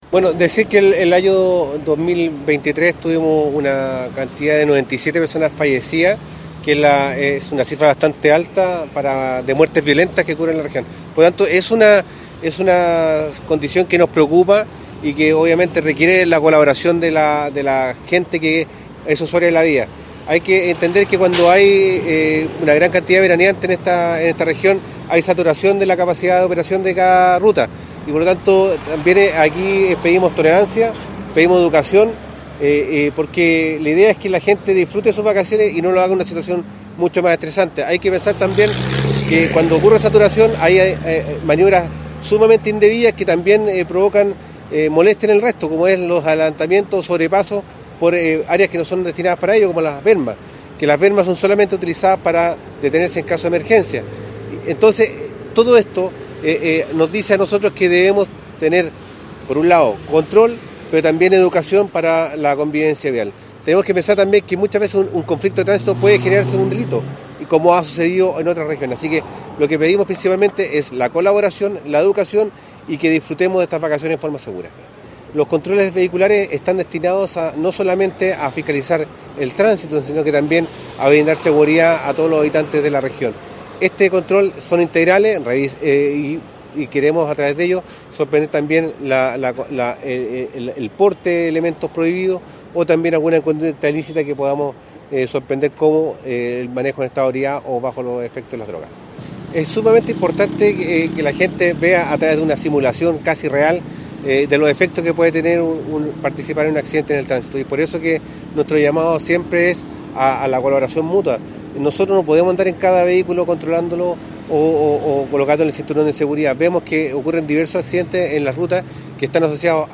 CAMPANA-TRANSITO-General-Carabineros-Juan-Munoz.mp3